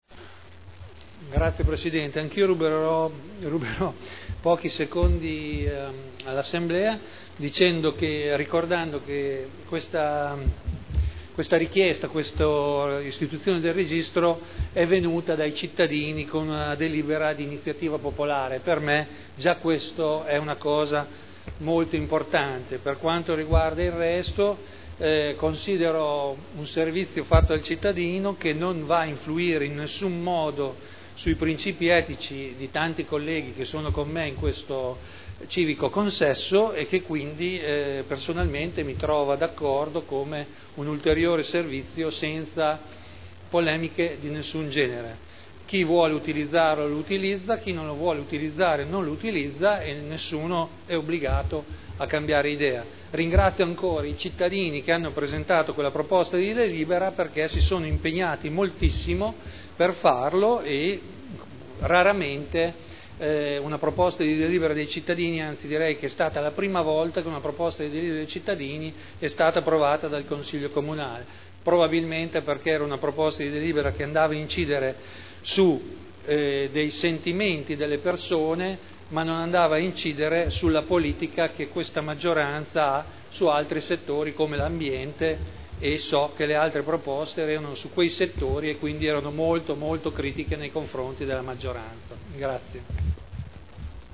Seduta del 17/01/2011. Interrogazione del consigliere Leoni (PdL) avente per oggetto: "Registri comunali testamento biologico ‘illegittimi’. Il Comune di Modena persevera nell’errore?"